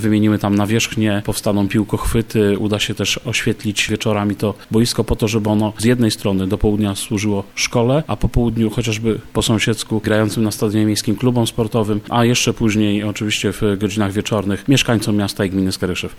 O tym co zostanie podjęte remontem i wymianą, mówi Dariusz Piątek burmistrz Skaryszewa: